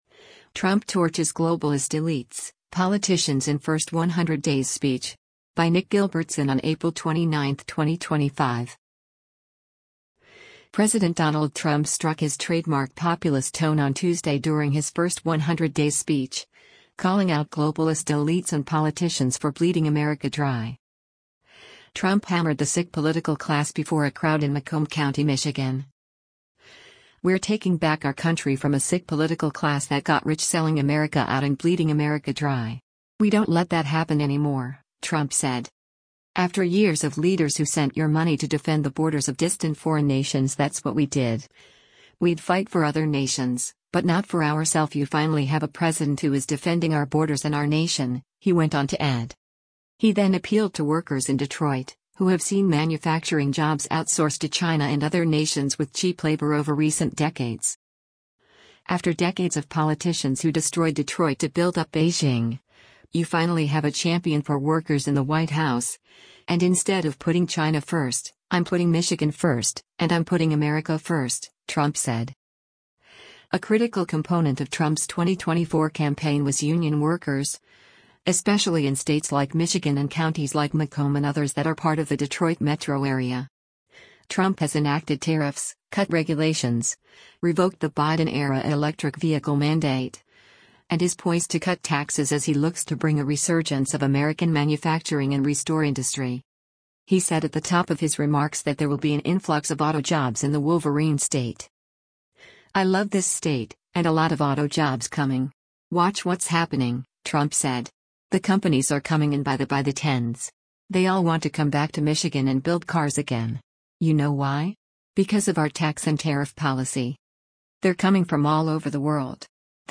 President Donald Trump speaks during a rally at Macomb Community College on April 29, 2025
Trump hammered the “sick political class” before a crowd in Macomb County, Michigan.